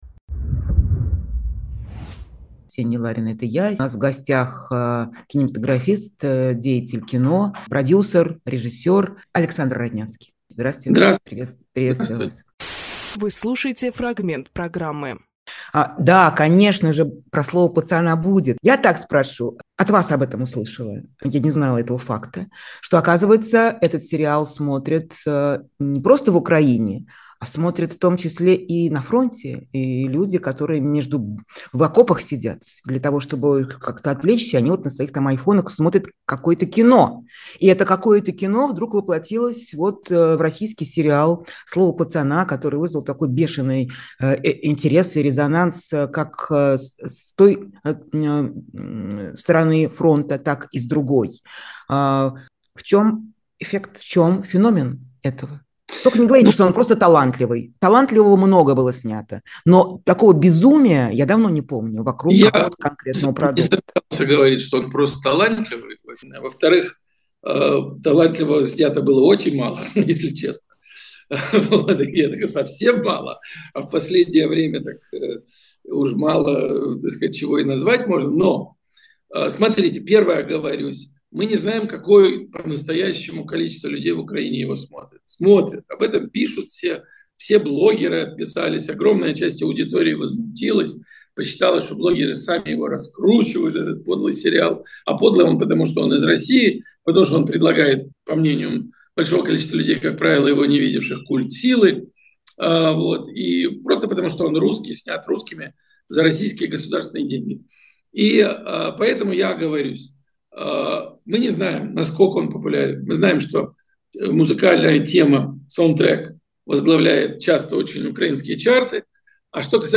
Фрагмент эфира от 16 декабря.